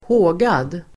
Uttal: [²h'å:gad]